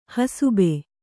♪ hasube